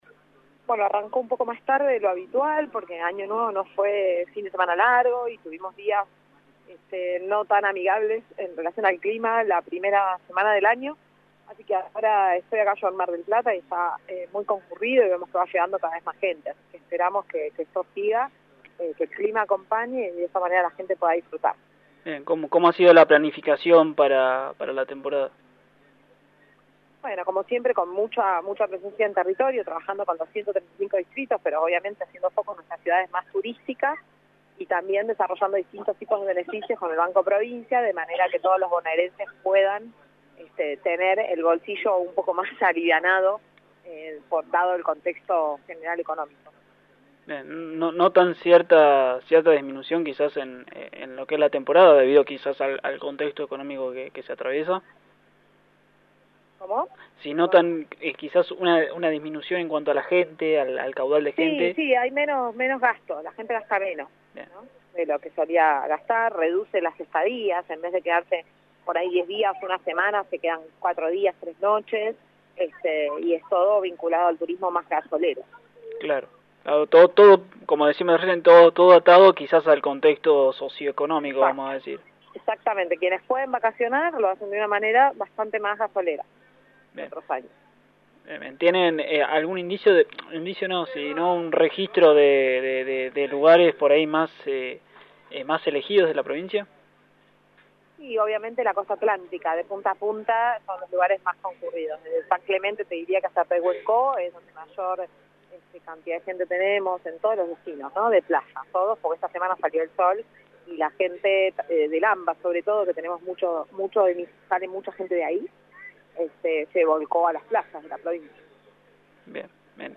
Lo consideró la subsecretaria de Turismo de la Provincia, María Soledad Martínez, en diálogo con Lu32.